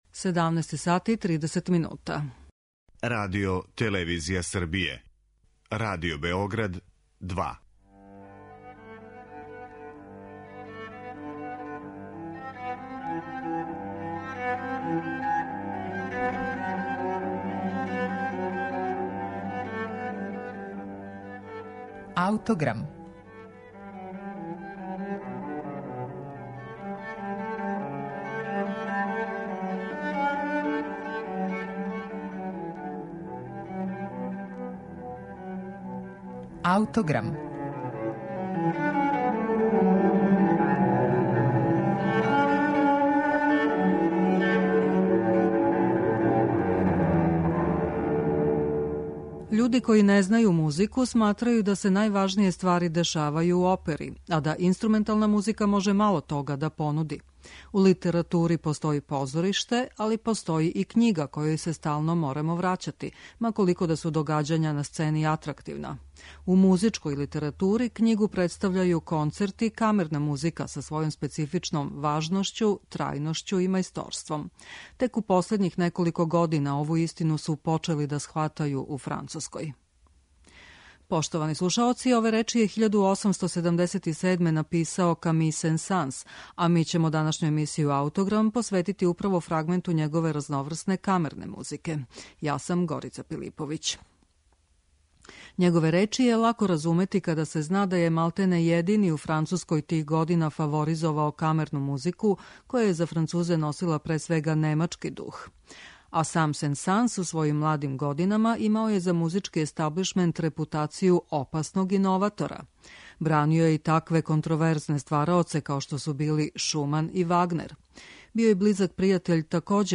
први клавирски трио